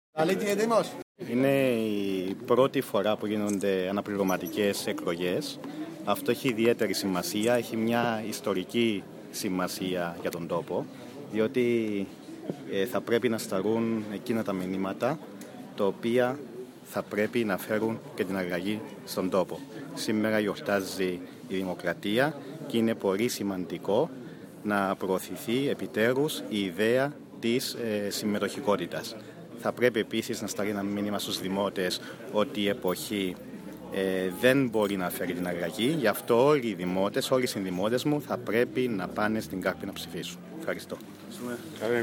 δήλωση